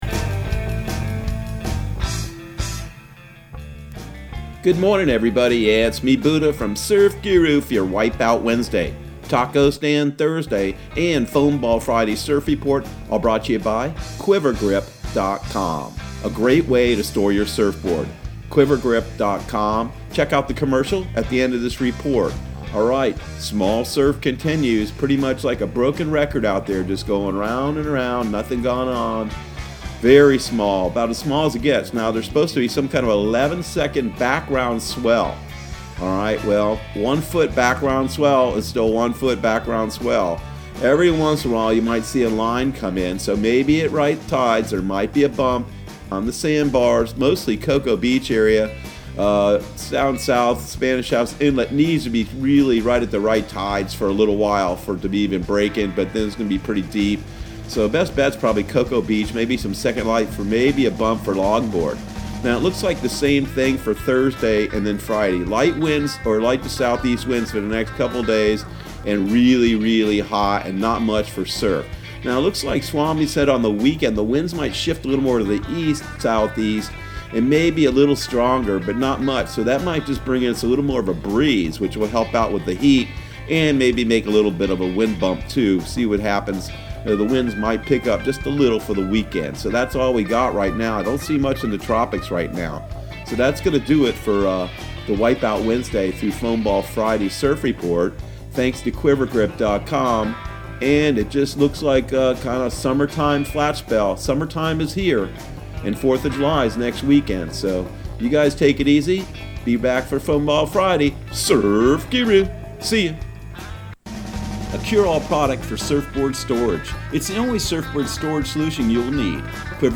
Surf Guru Surf Report and Forecast 06/24/2020 Audio surf report and surf forecast on June 24 for Central Florida and the Southeast.